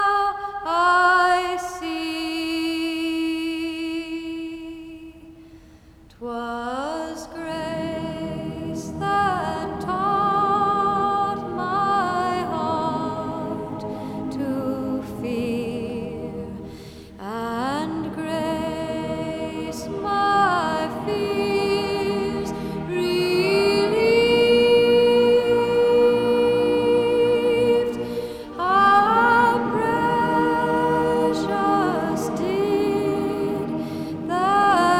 Жанр: Поп / Рок / Фолк-рок